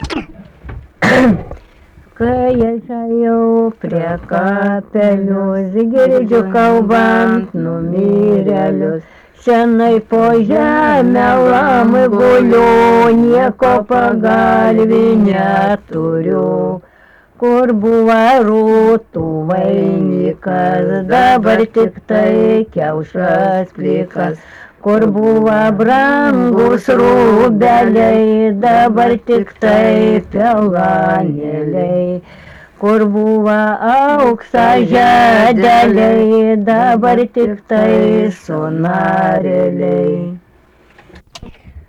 daina
Erdvinė aprėptis Krapiškis
Atlikimo pubūdis vokalinis